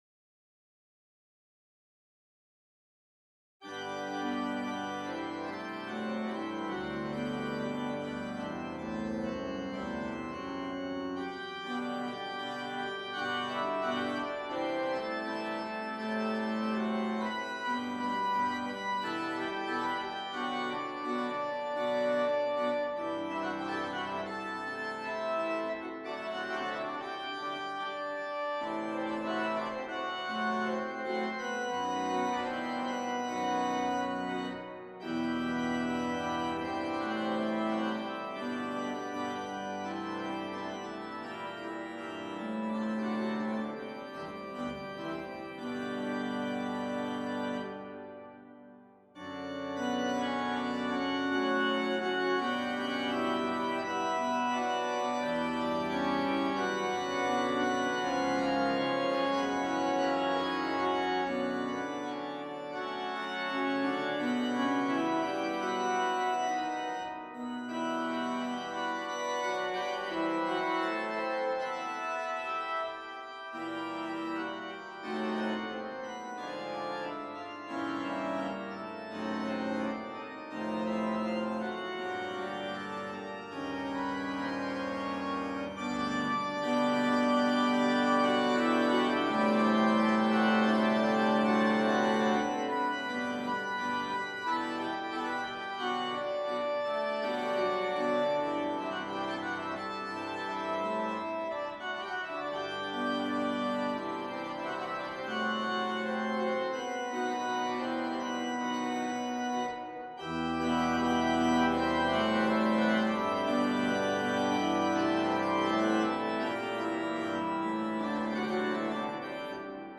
Das gleiche Stück mit verschiedenen Einstellungen: